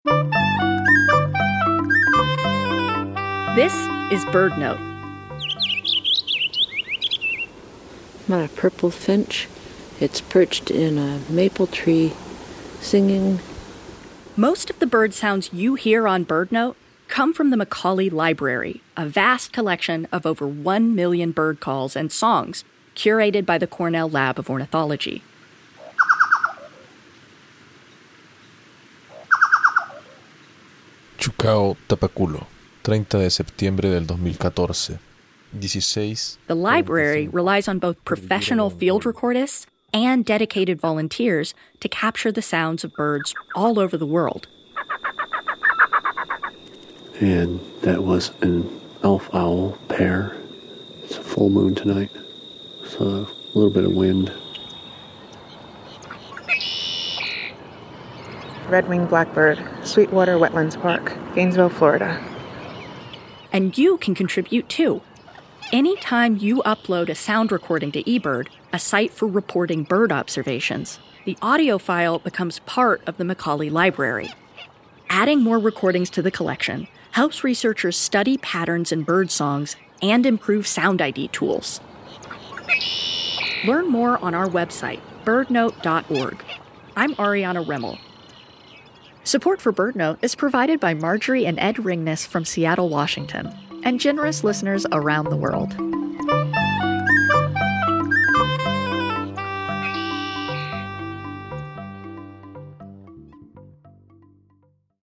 Most of the bird sounds you hear on BirdNote come from the Macaulay Library, a vast collection of over one million bird calls and songs curated by the Cornell Lab of Ornithology. The library relies on both professional field recordists and dedicated volunteers to capture the sounds of birds all over the world.